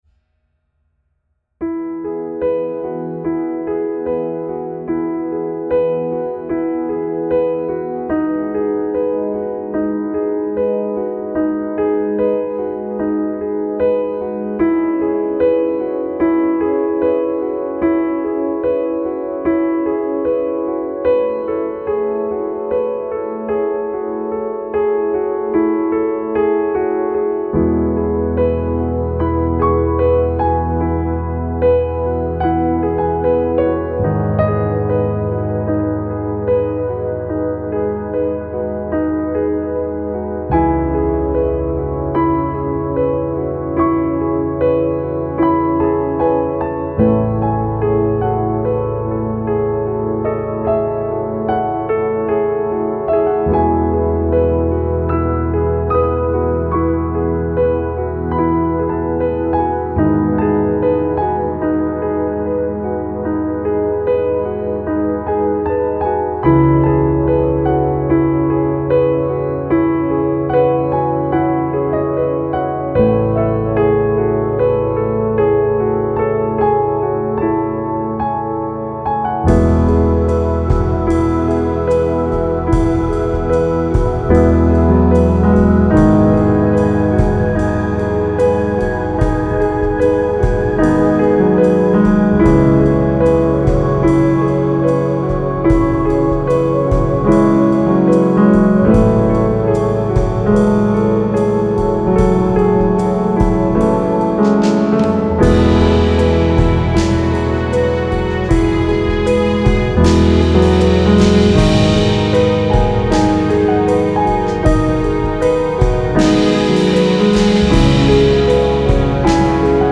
آرامش بخش پست راک موسیقی بی کلام